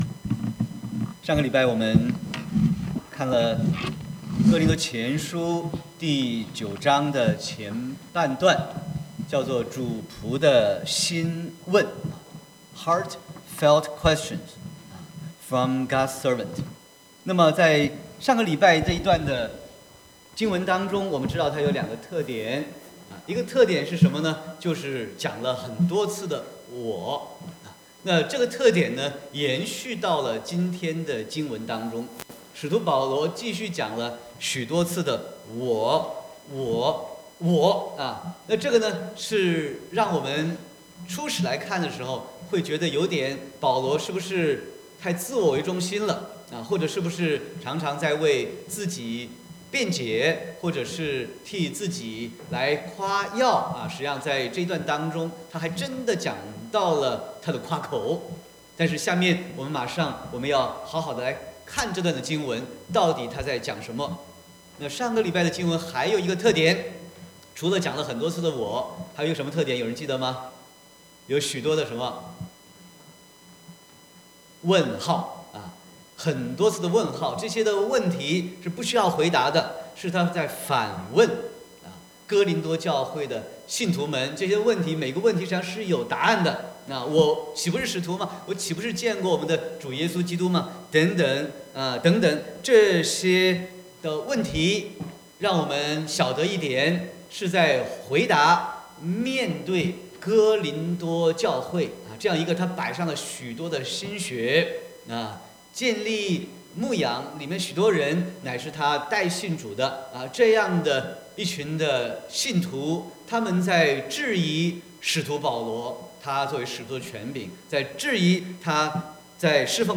Sermon 03/31/2019